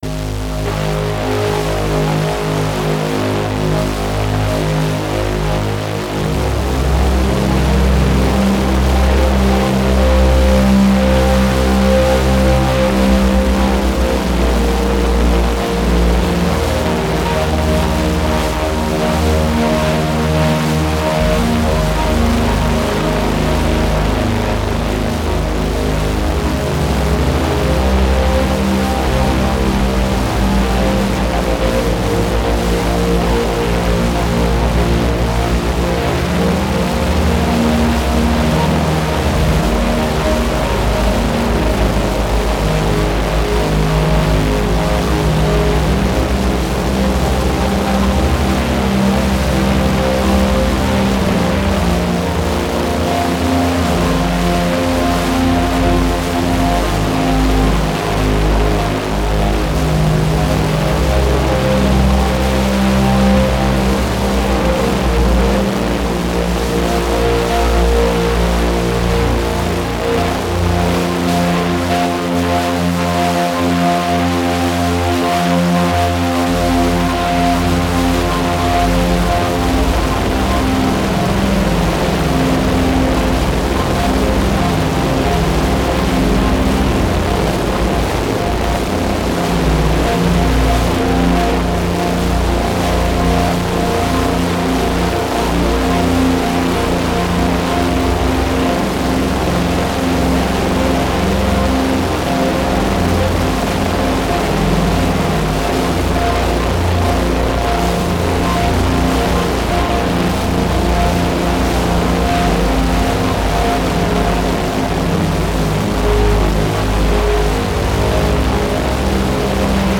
Genre: Noise.